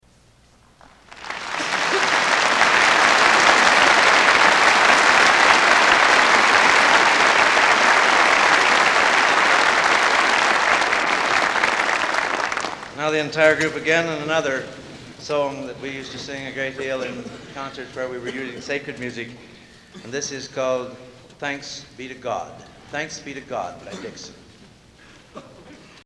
Collection: Gala Anniversary Concert 1958
Genre: | Type: Director intros, emceeing